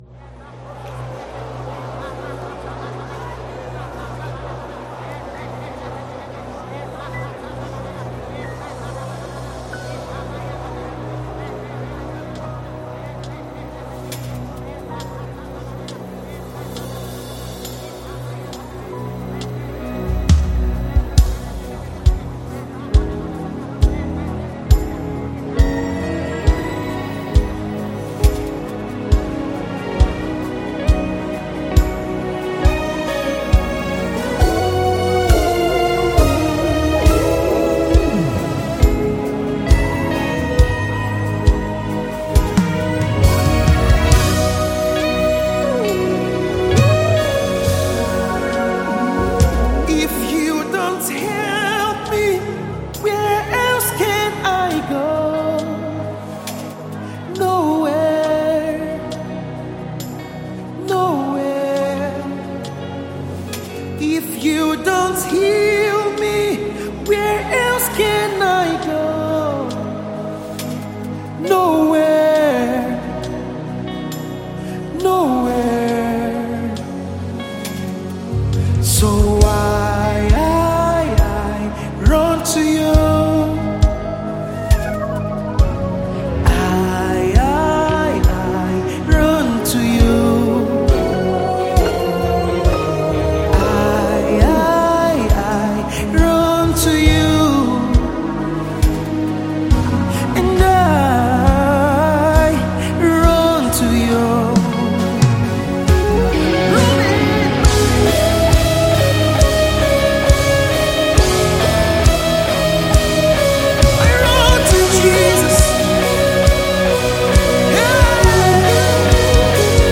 prayer song